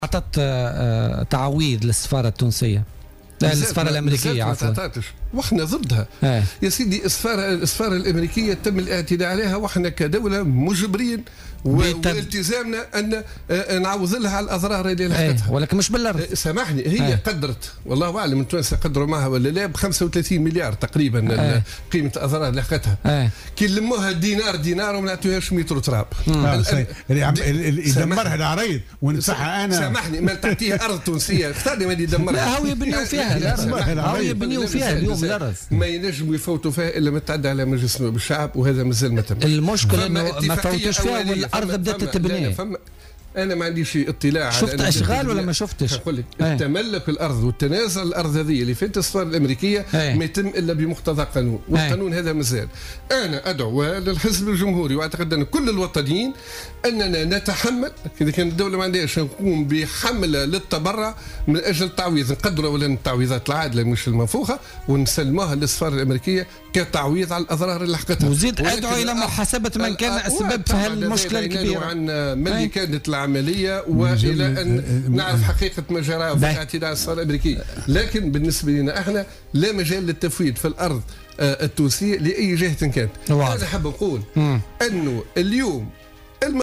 وأكد ضيف "بوليتيكا" على "الجوهرة أف أم" أنه لم يصدر أي قانون حول التفويت في قطعة أرض لفائدة السفارة الأميركية، مشددا بالقول إن التنازل عن هذه الأرض لا يتم إلا بمقتضى قانوني.